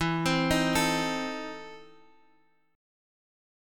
EM7sus4#5 chord